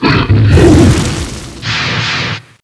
revenant_zbs_fireball1.wav